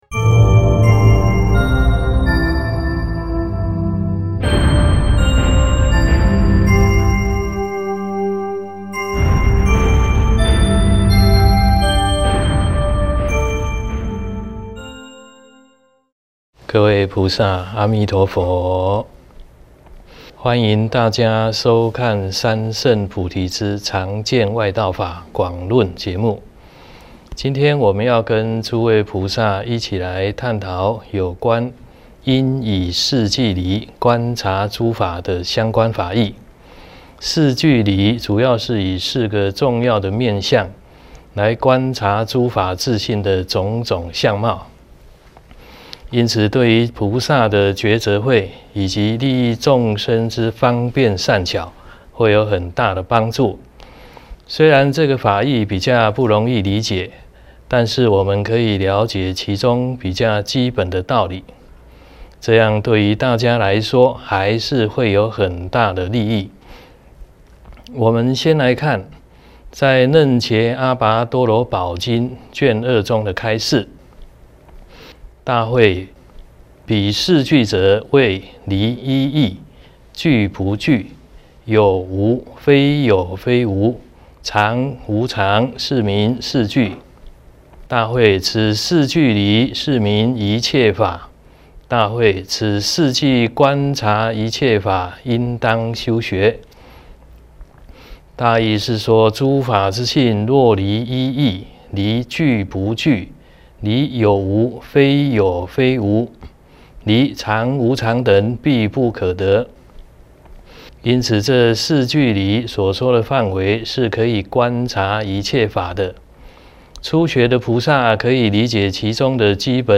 三乘菩提系列讲座，正觉同修会影音，同修会音频，同修会视频